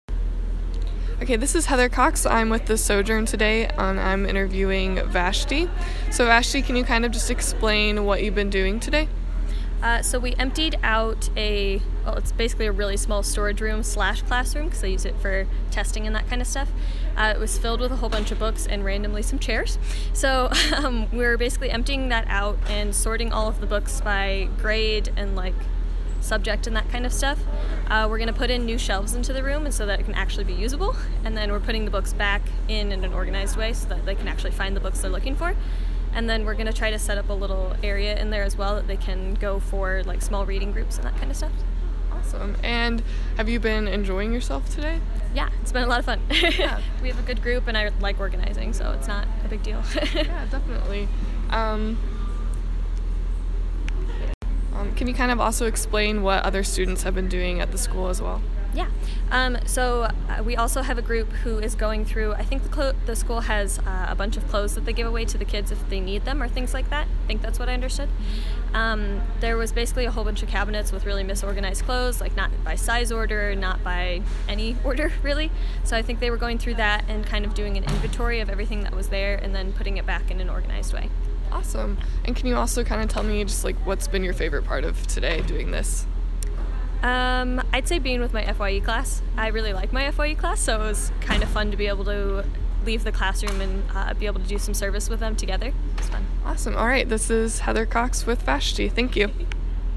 NSO freshman talks about serving at Allen Elementary School